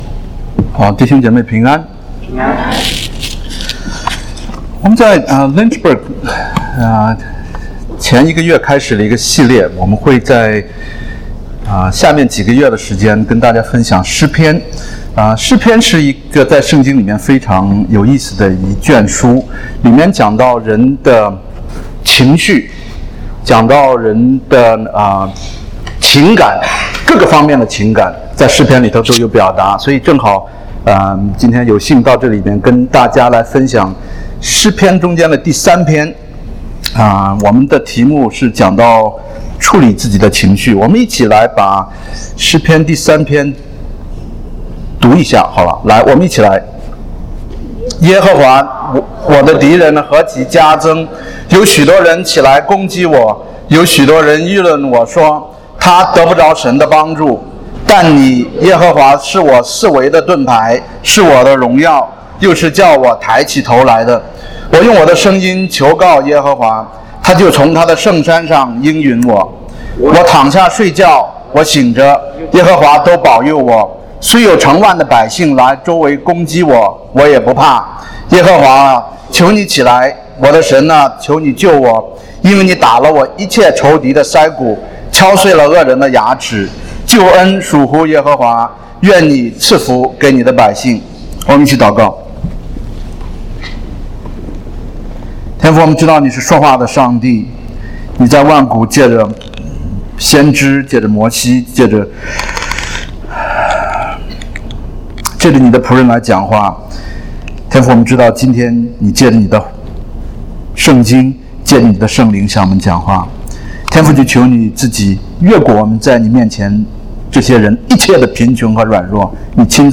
證道